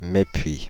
Mespuits (French pronunciation: [mɛpɥi]
Fr-Paris--Mespuits.ogg.mp3